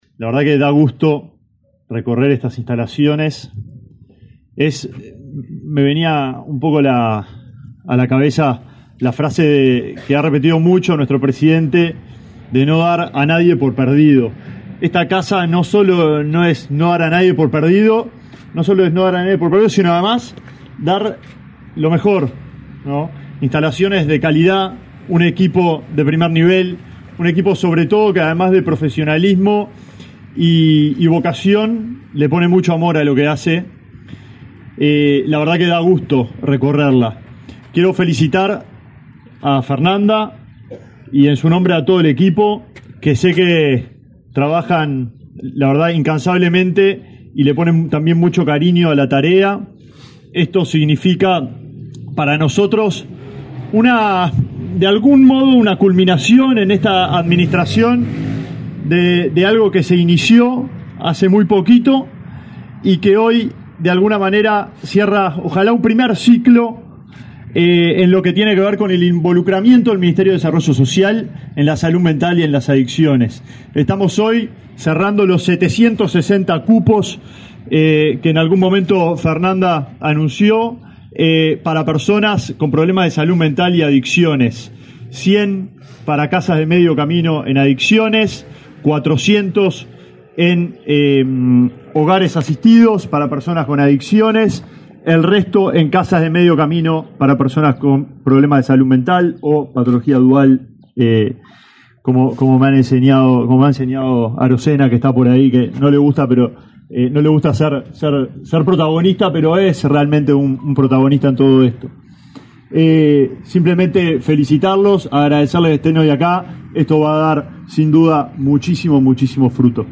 Palabras del ministro de Desarrollo Social, Alejandro Sciarra